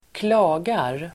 Uttal: [²kl'a:gar]